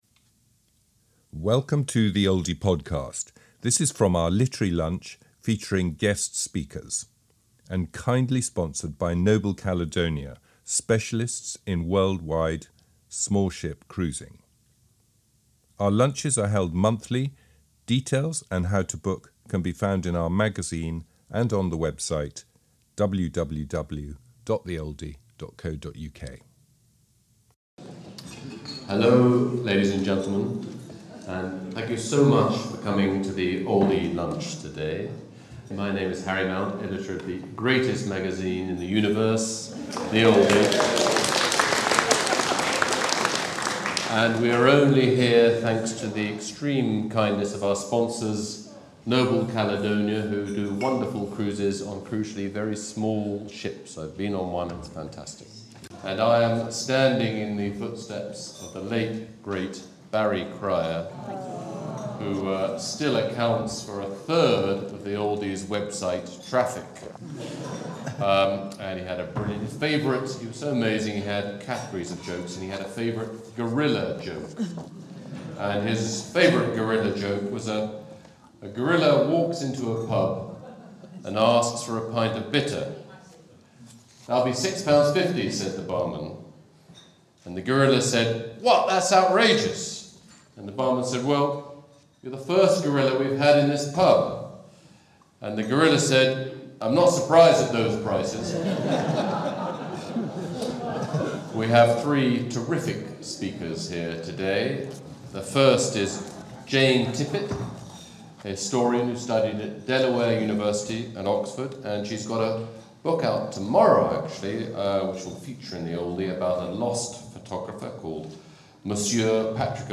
Radio Oldie / Oldie Literary Lunch Recording